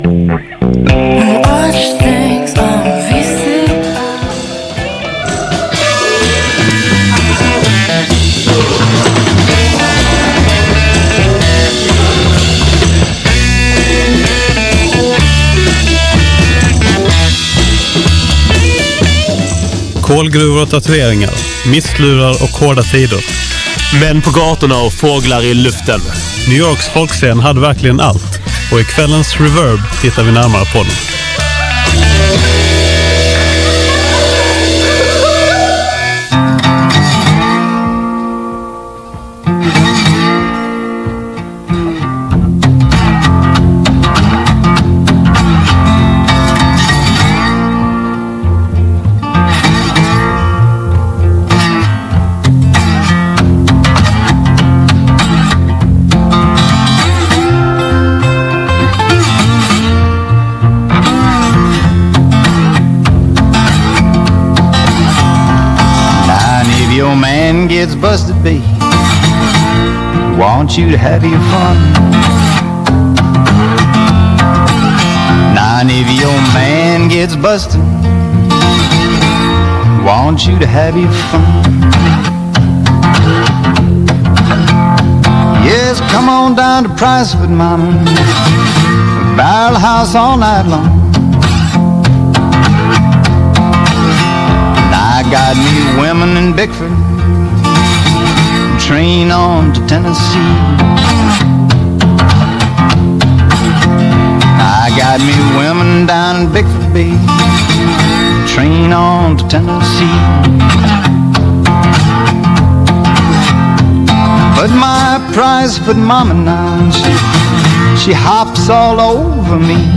Good Times in New York Town - Reverbs Folkmusikspecial I början av 60-talet blev det plötsligt populärt att ta på sig en basker, hänga en akustisk gura kring halsen i ett färglatt axelband och sjunga ändlösa tonsatta filippiker mot krig och orättvisor, med fler stavelser och färre ackord än någon trott var möjligt.